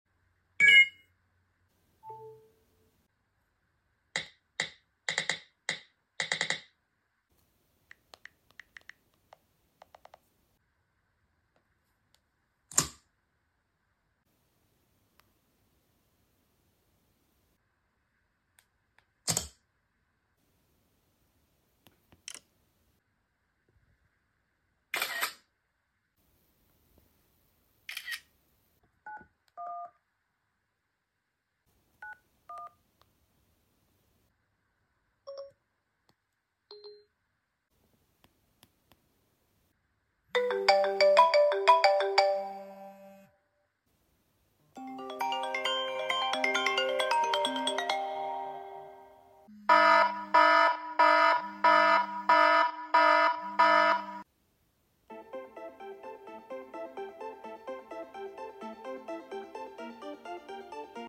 ⏰iPhone 5 on iOS 6 sound effects free download
⏰iPhone 5 on iOS 6 vs. iOS 17 Sounds